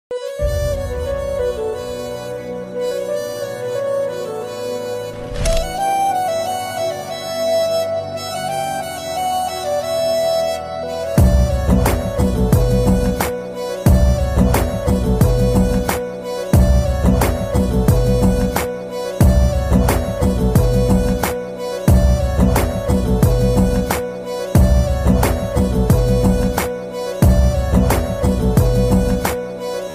A soulful Telugu romantic melody
Features lush orchestration
Sweet & airy.